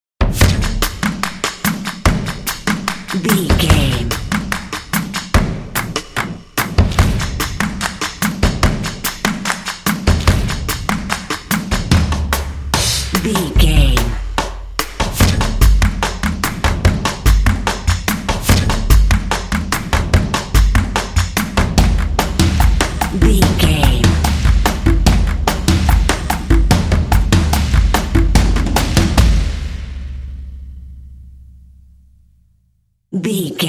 Epic / Action
Atonal
confident
tension
drumline